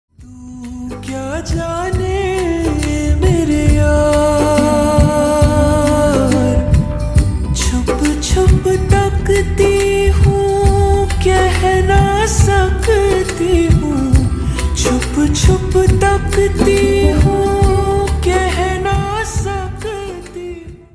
Hindi Songs